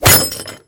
propellerfail.mp3